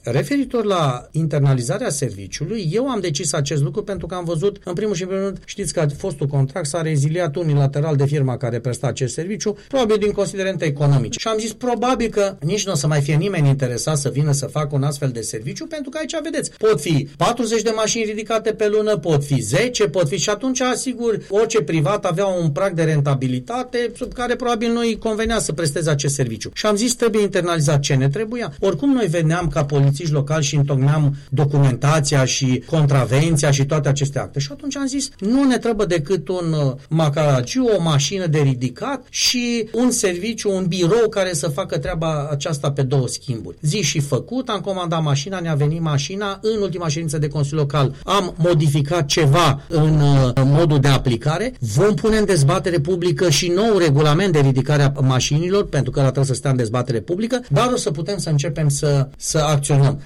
Primarul Gabriel Pleșa a explicat la Unirea FM de ce s-a internalizat serviciul de ridicare a mașinilor parcate neregulamentar.